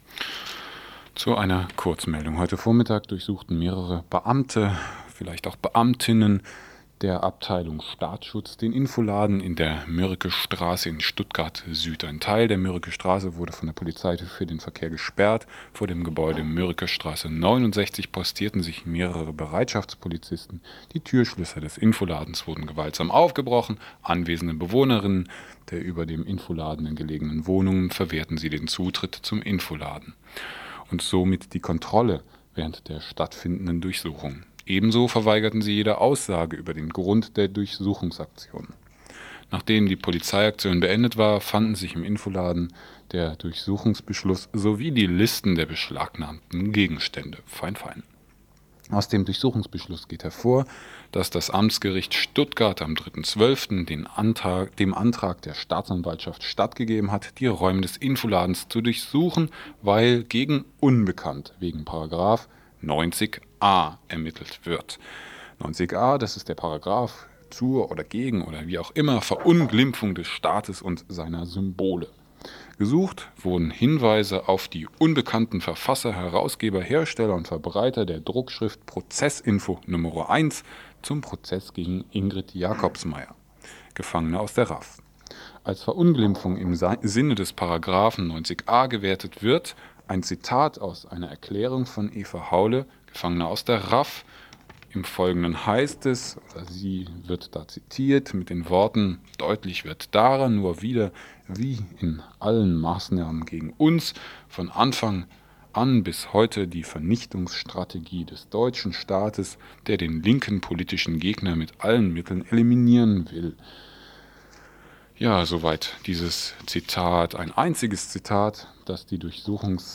Kommentierte Verlesung der Pressemitteilung